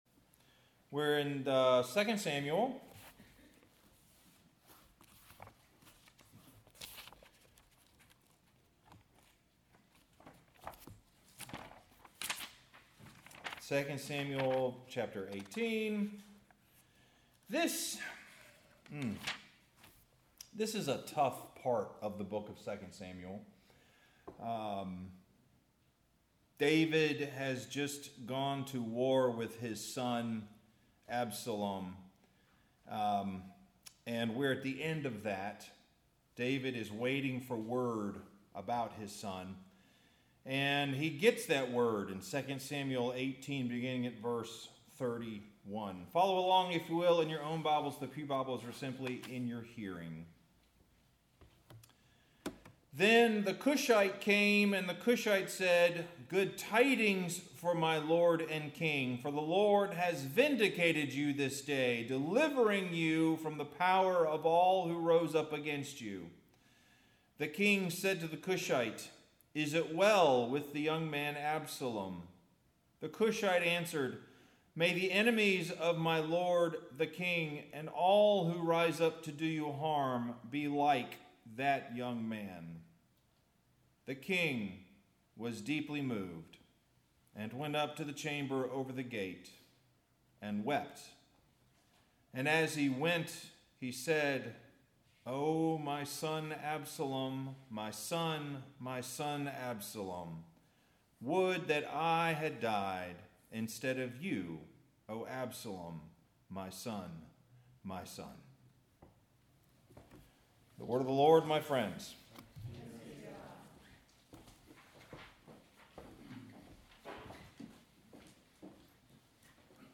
Sermon – Two Wrongs Don’t Make a Right